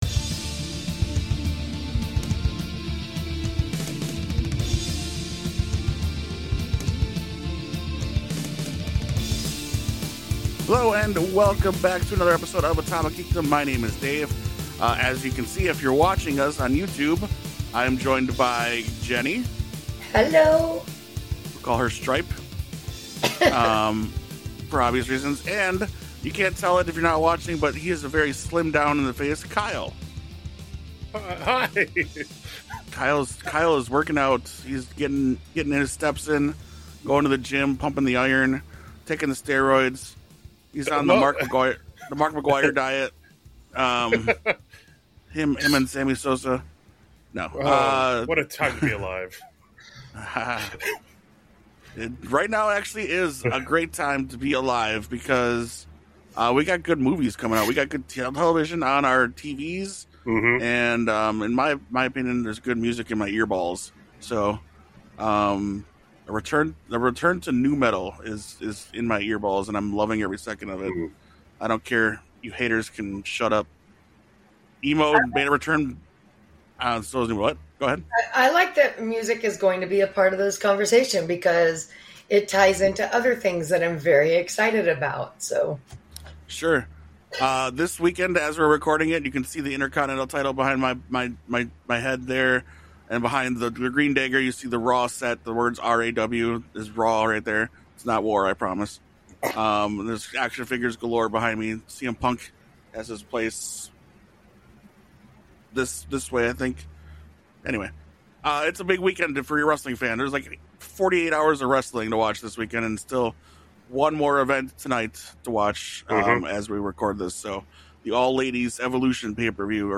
This week on the podcast the boys sit down, roundtable style to discuss why the MCU has worked so well and the DCEU has not…or has it? This is the final part of a two episode series on the big comic book world debate.